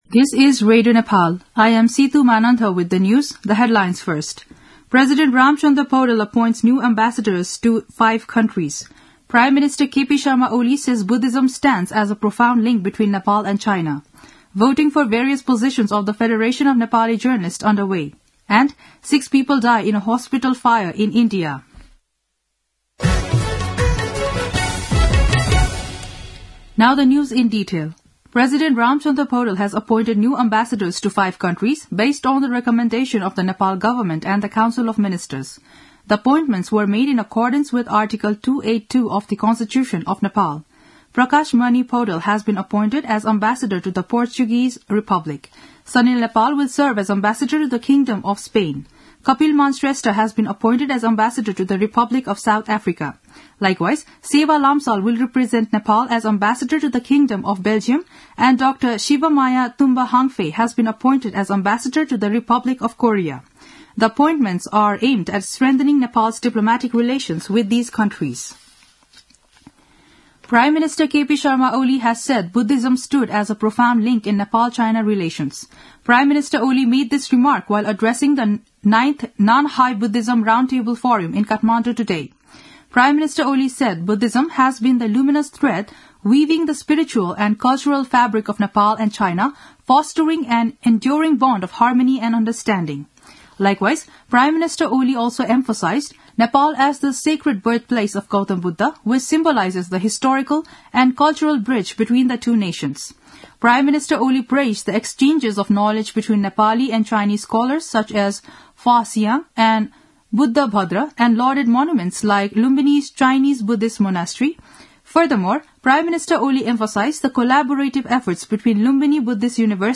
दिउँसो २ बजेको अङ्ग्रेजी समाचार : १८ पुष , २०२६
2-pm-english-news-1-10.mp3